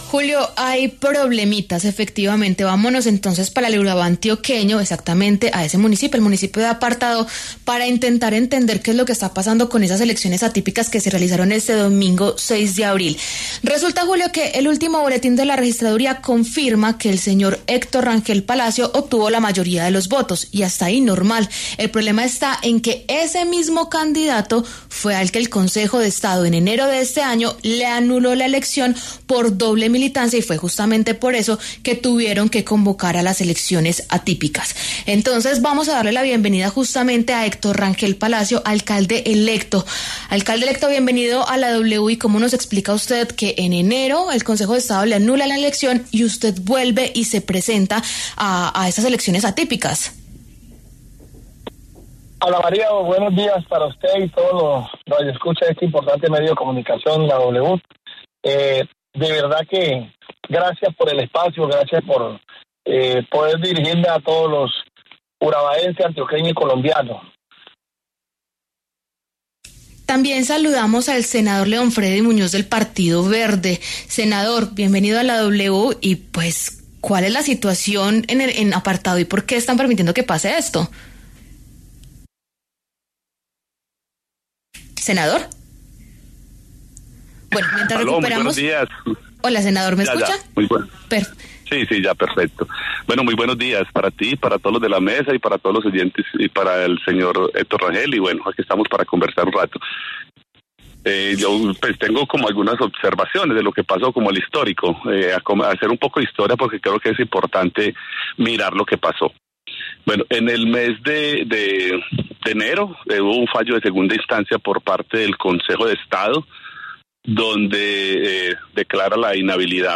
Ante los microfonos de W Radio, Héctor Rangel Palacio se pronunció sobre su elección como alcalde tras la anulación del Consejo de Estado.